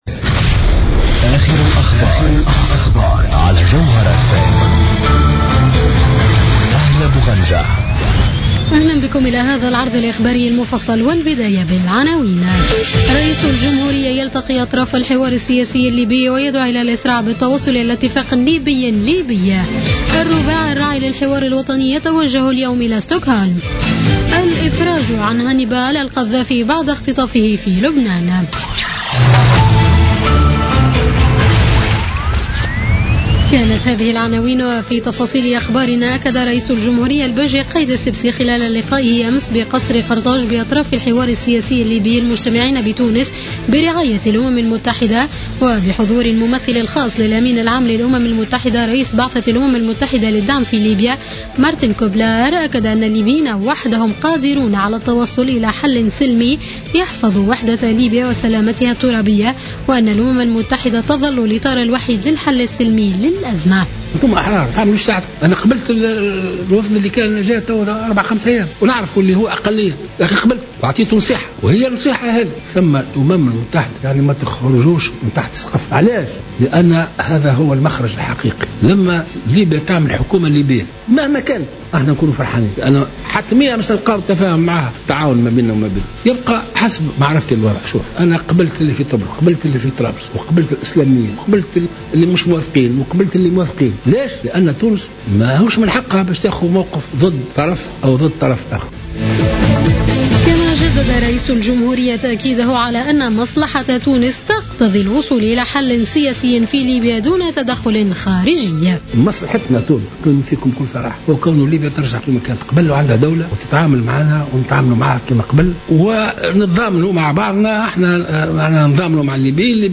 نشرة أخبارمنتصف الليل ليوم السبت 12 ديسمبر 2015